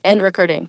synthetic-wakewords
ovos-tts-plugin-deepponies_Billie Eilish_en.wav